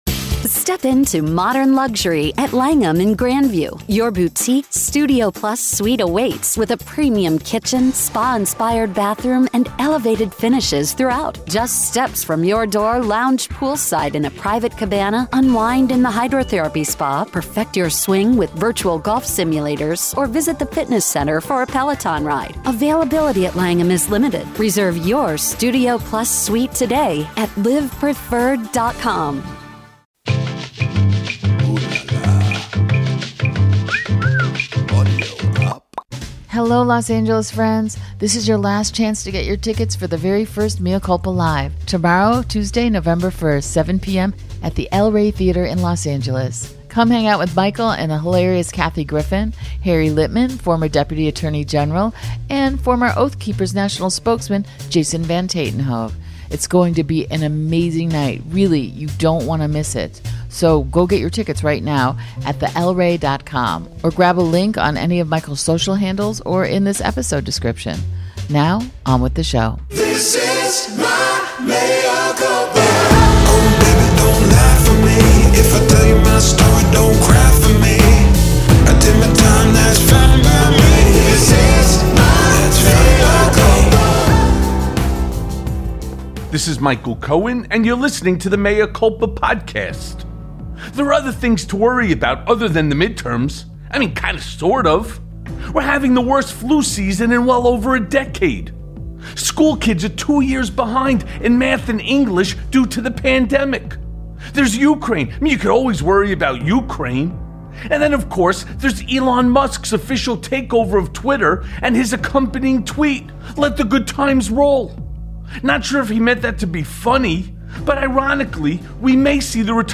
Breaking!!! The Election Nightmare Has Already Started + A Conversation with Norm Eisen